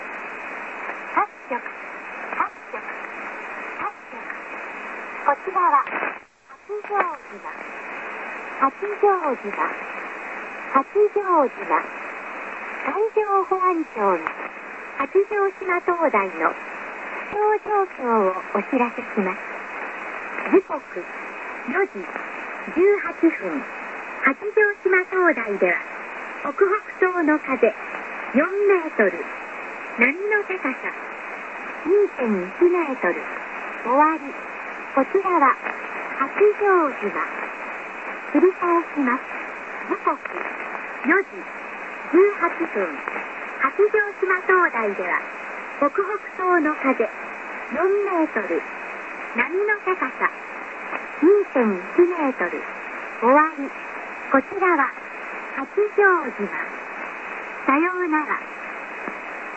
全国の船舶気象通報
録音環境　・受信場所：尾張旭市　・アンテナ：ＡＬＡ１５３０(東向き, 北向き)　・受信機：ＪＲＣ ＮＲＤ−５４５ｘ２,ＰＥＲＳＥＵＳ
登録内容　＊採用の時間は、結構空電が多くパルス性のノイズレベルが高いので注意してお聞き下さい。